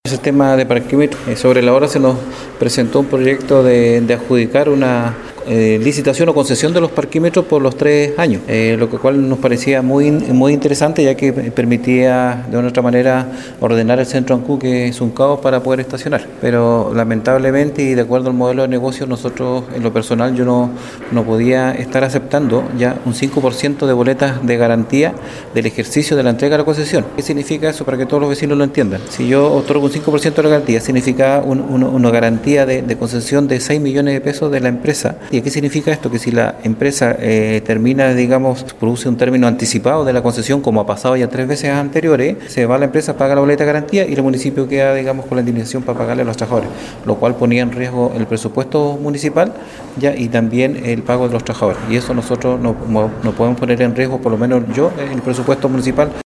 03-CONCEJAL-ALEX-MUÑOZ.mp3